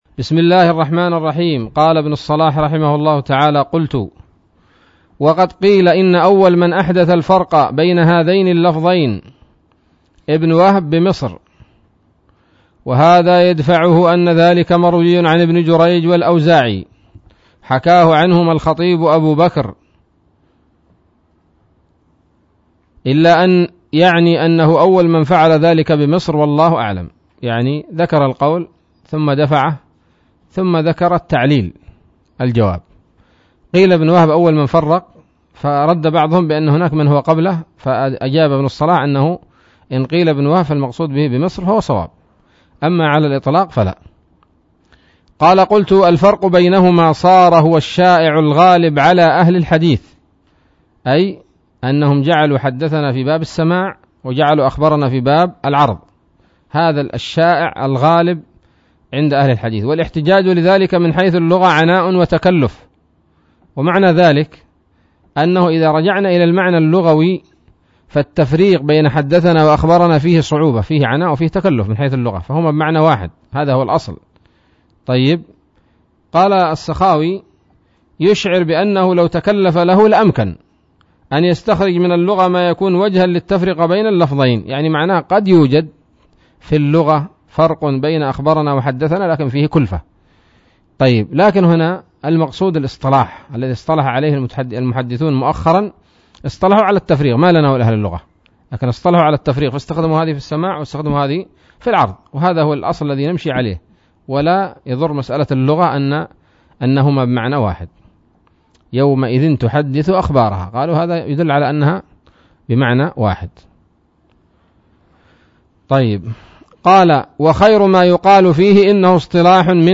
الدرس الثاني والستون من مقدمة ابن الصلاح رحمه الله تعالى